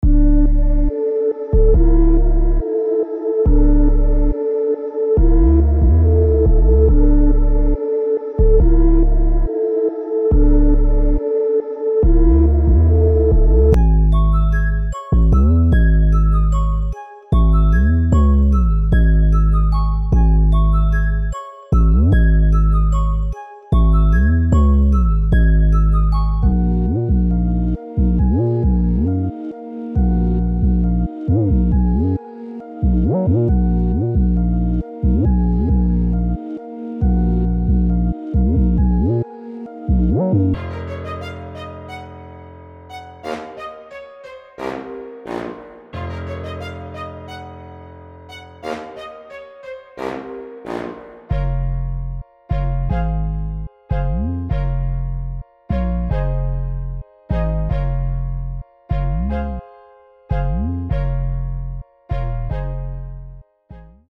• Mini Construction Kit
• Includes Drums